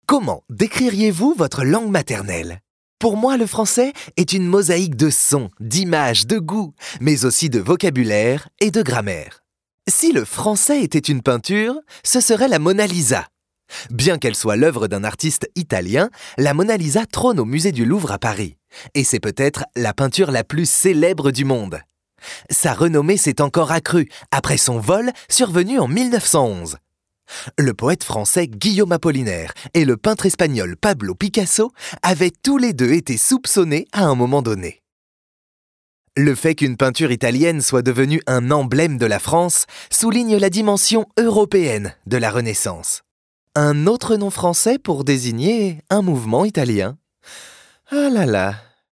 Natural, Versatile, Friendly
Explainer